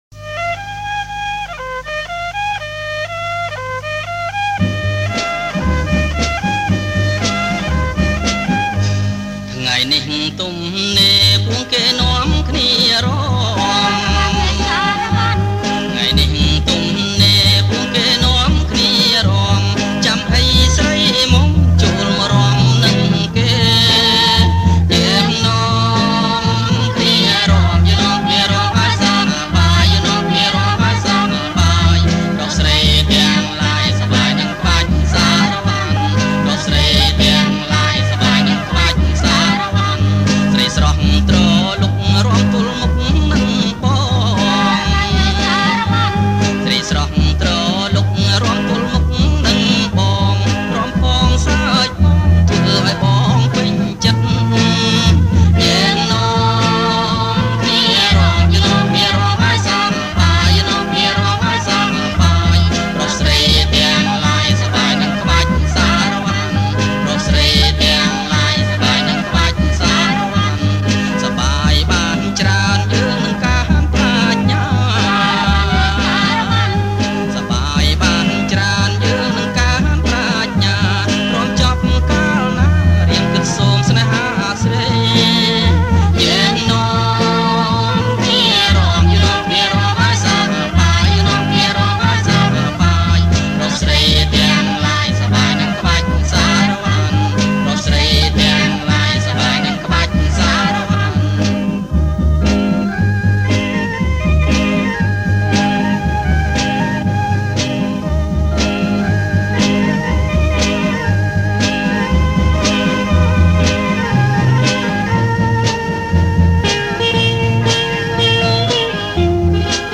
• ប្រគំជាចង្វាក់ សារ៉ាវ៉ាន់